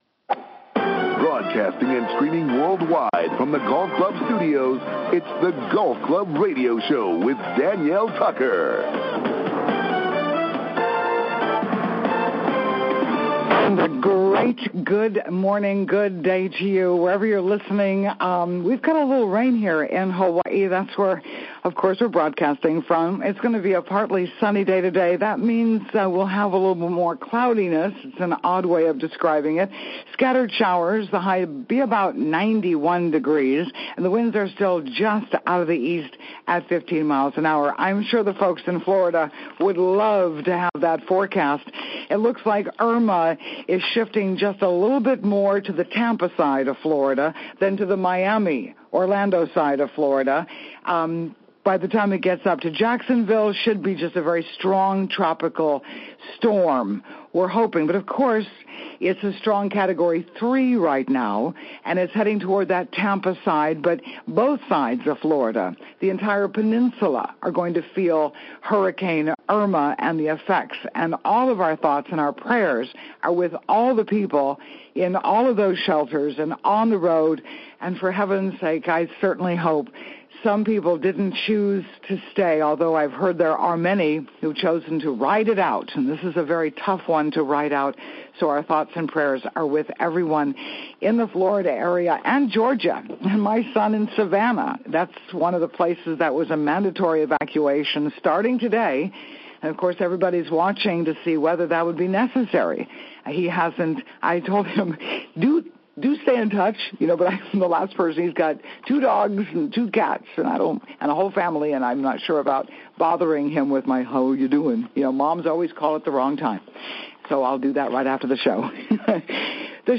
Interview with Gary Player